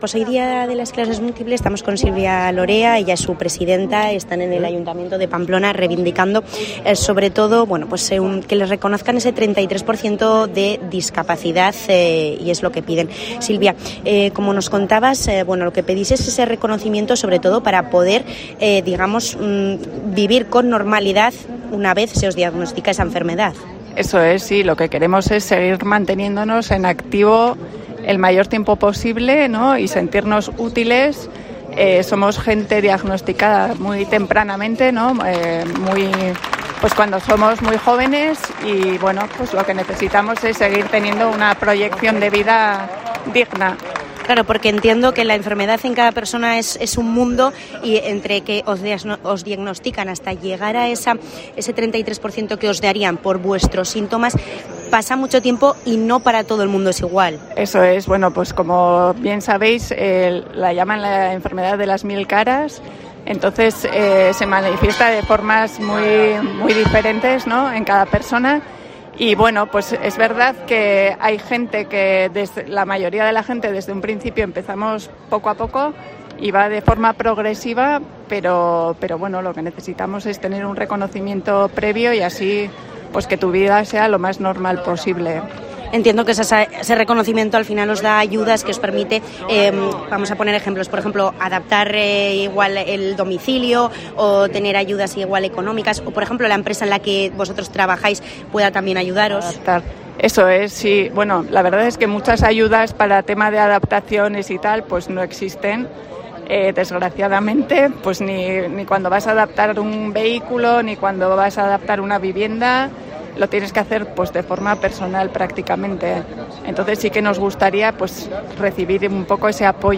ha hablado para los micrófonos de Cope Navarra en el Día Mundial de la Esclerosis Múltiple, después de una concentración frente al Ayuntamiento de Pamplona.